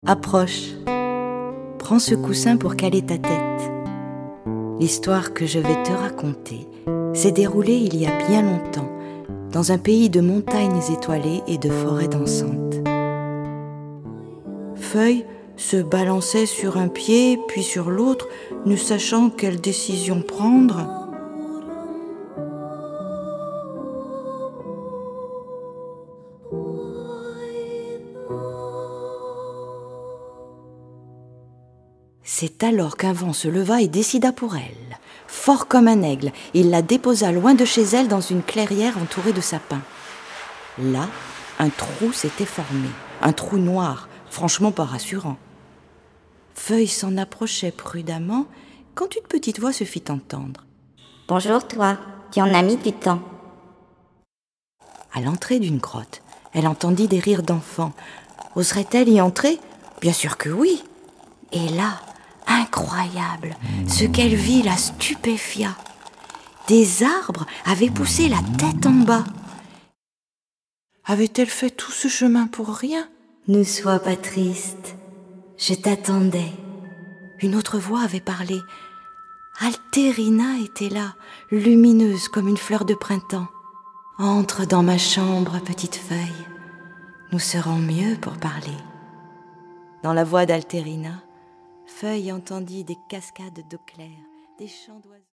CD avec le conte lu : L’aventure de la collection ‘Berceuses’ continue !
contes_graines.mp3